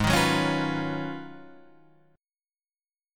G# Minor 13th